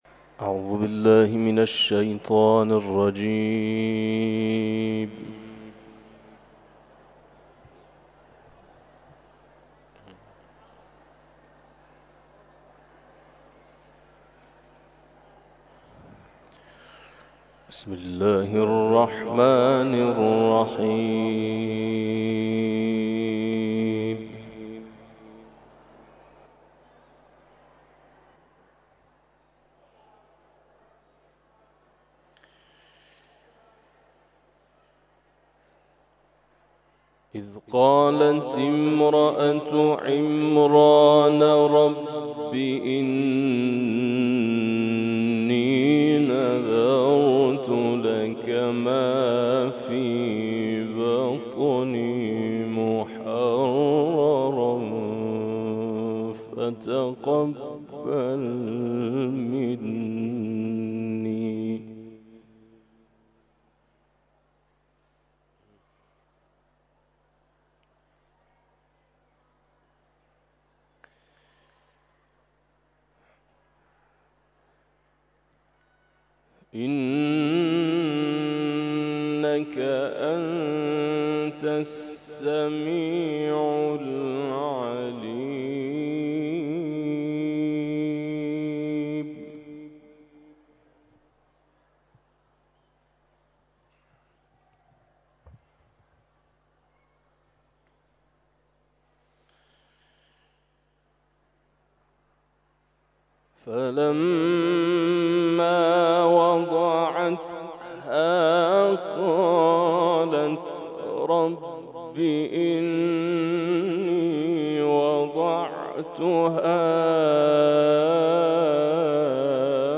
تلاوت ظهر